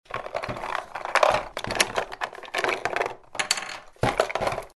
Звуки карандаша
Здесь вы найдете скрип при письме, стук о поверхность, шелест заточки и другие ностальгические эффекты.